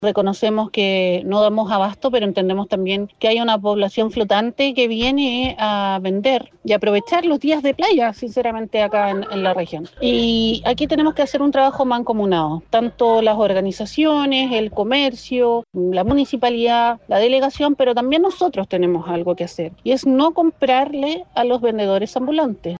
En tanto, Nancy Díaz, concejala de Viña del Mar y militante del Frente Amplio, enfatizó que es necesario hacer un trabajo más coordinado para enfrentar esta problemática e hizo un llamado a la ciudadanía a no comprarle a los vendedores ambulantes.